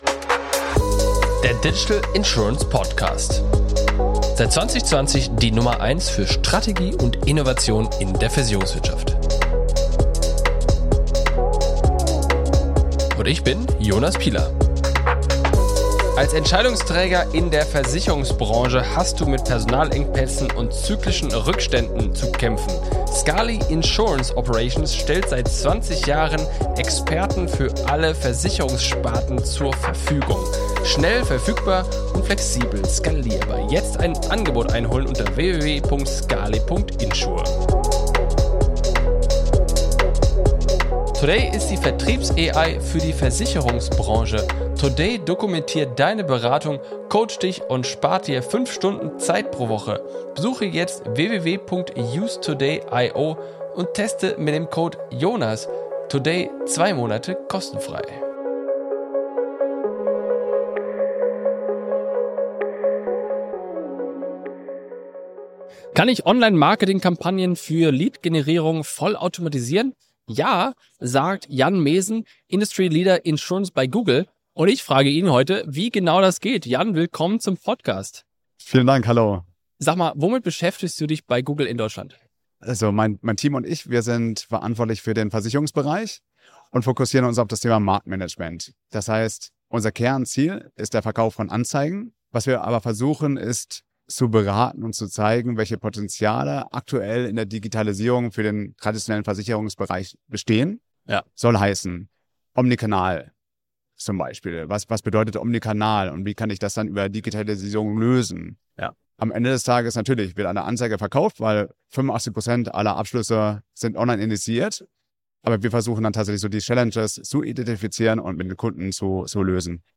Die Episode haben wir auf der diesjährigen insureNXT in Kooperation mit dem InsurLab Germany e.V. aufgezeichnet.